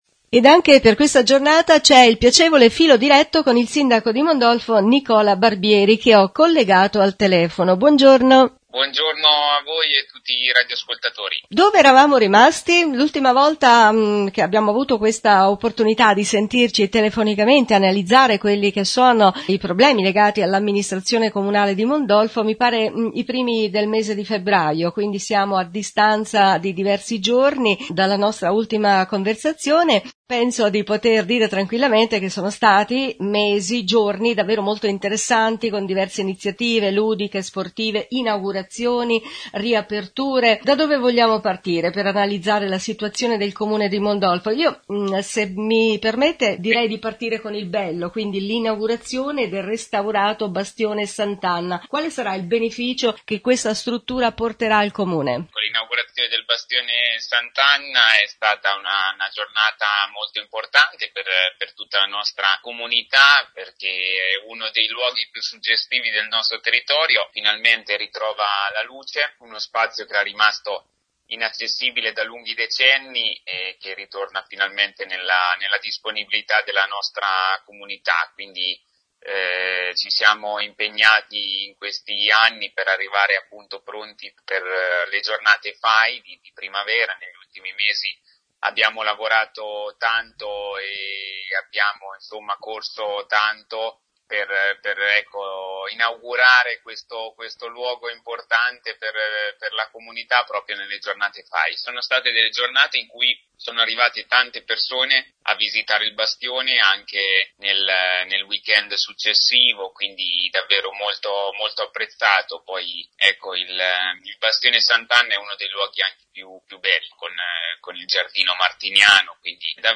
il-Sindaco-su-NEW-RADIO-STAR-risponde-e-comunica-con-i-cittadini.mp3